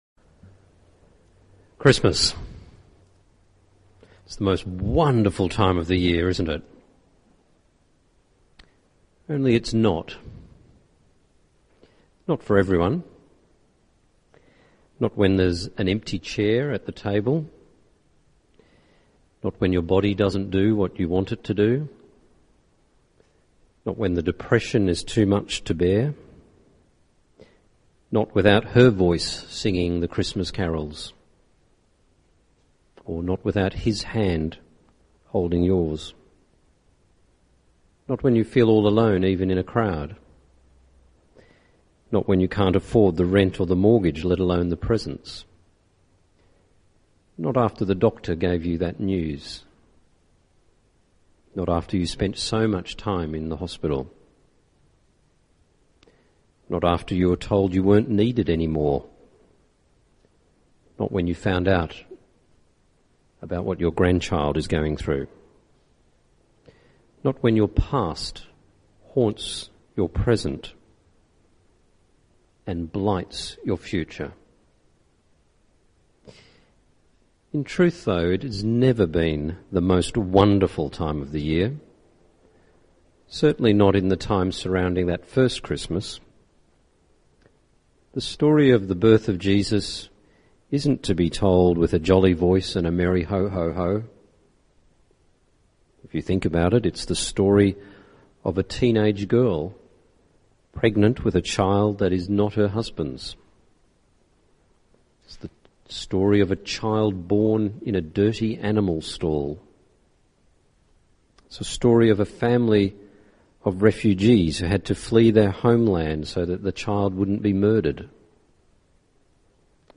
This sermon is not part of a Sermon Series and stands alone.